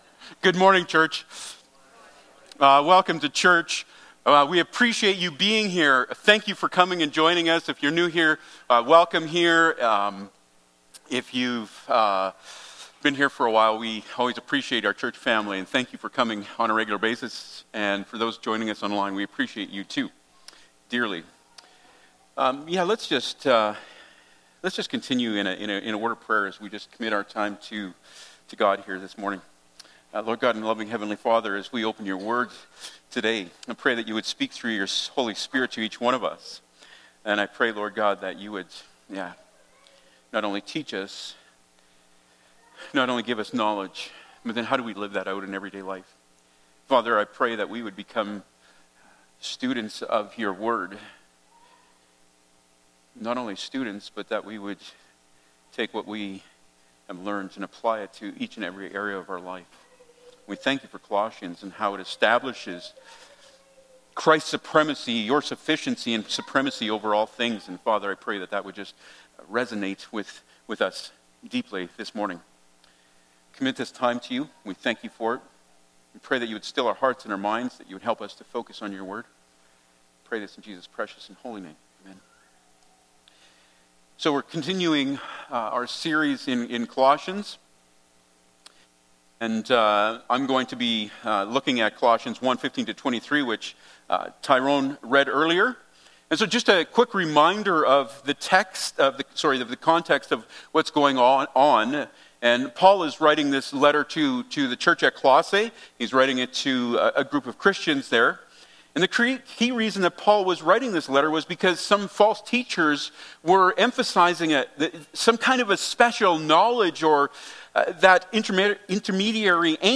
Series: Colossians - Jesus at the Core Passage: Col. 1: 15-23 Service Type: Sunday Morning